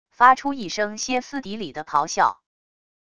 发出一声歇斯底里的咆哮wav音频